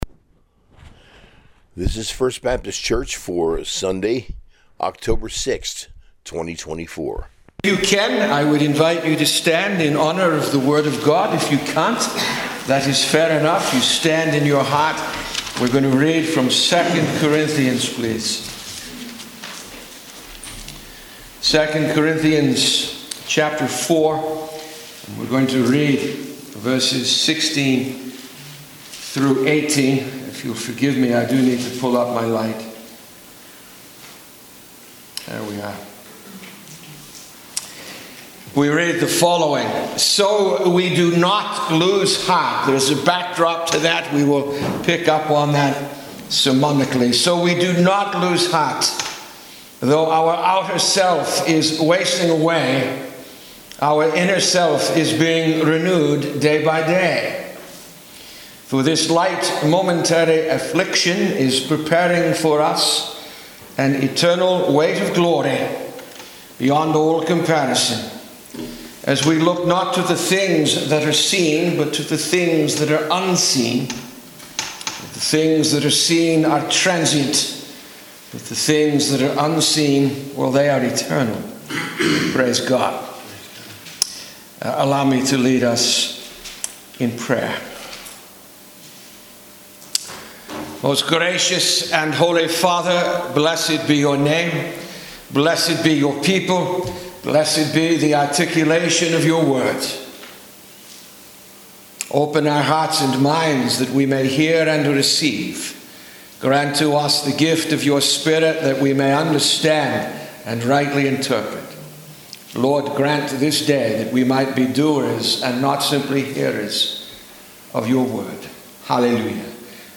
Sunday Sermon from II Corinthians 4:16-18